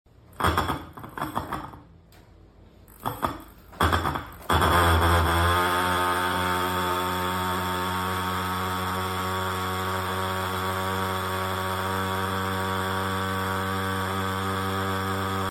Kita belanja brother ni bunyi mesin fogging Agro AF35